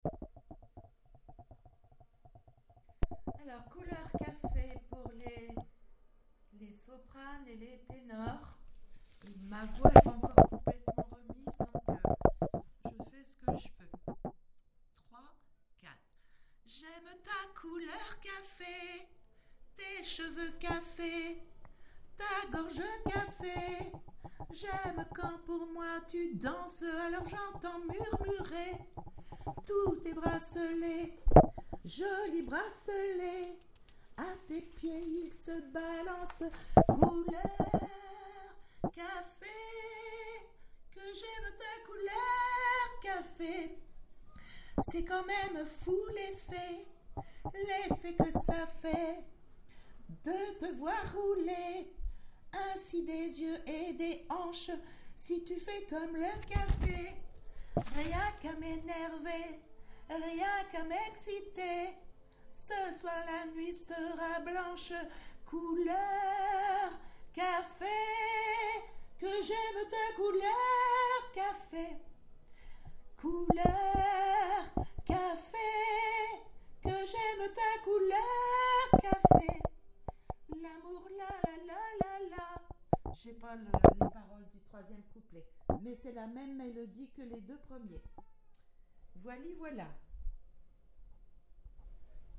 Couleur Café Sop Ténor